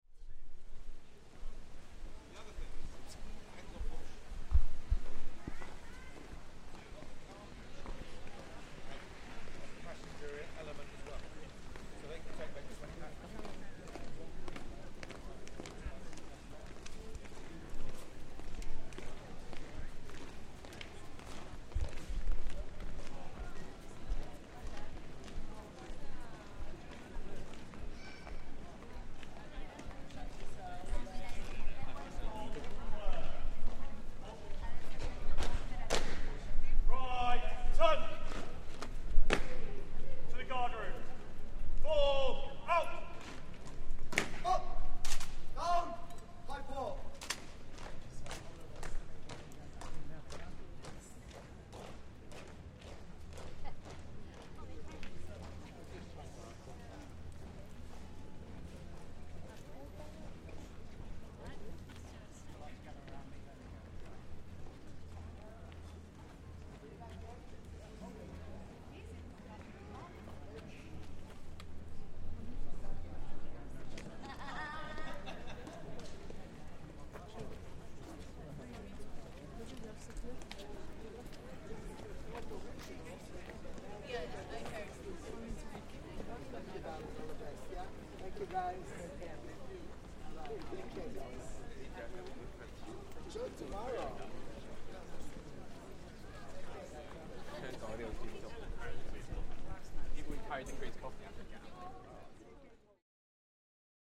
Ceremonial changing of the guard at the Tower of London - sounds of marching, shouted commands, and a throng of tourists watching the ceremony at the world-famous UNESCO World Heritage site.